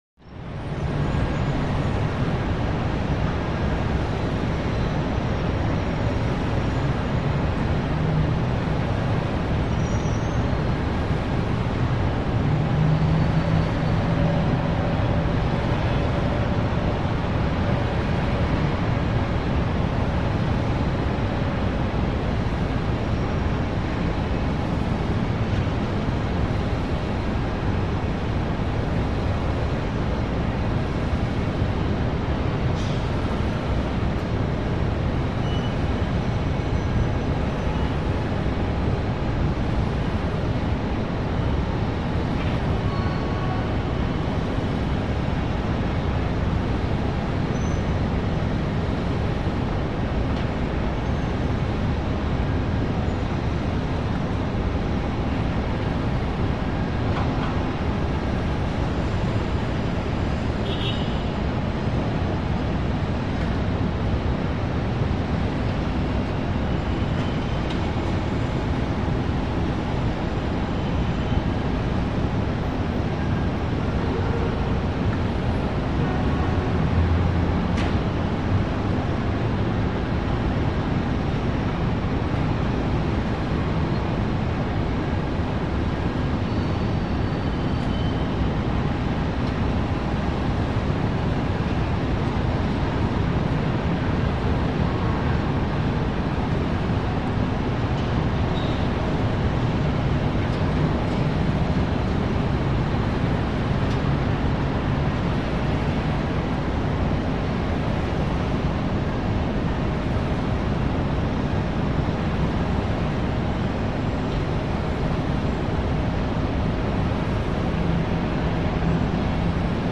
TrafficInteriorPOV CT052001
Traffic, Interior Point Of View, Open Window. Very High Perspective. Heavy Traffic Drone With A Few Specific Honks And Brake Squeaks. Could Play For Rooftop Perspective.